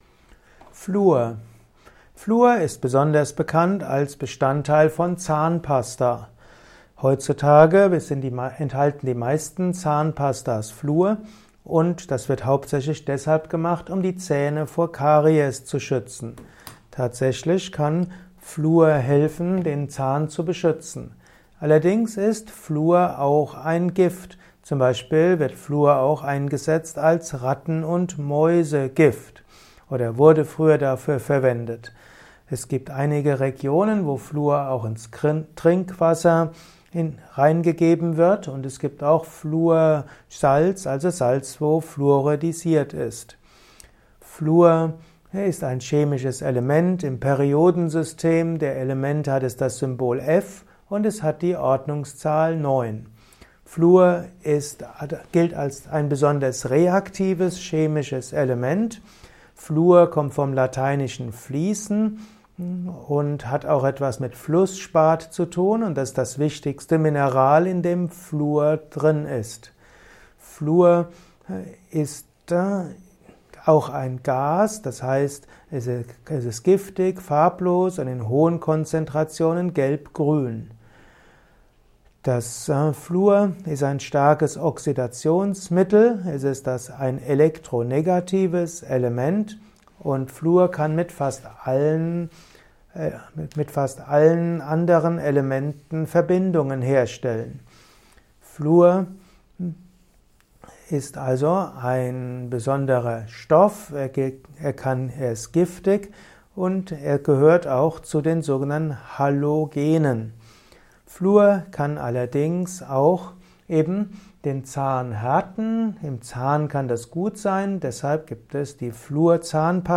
Ein Kurzvortrag über Fluor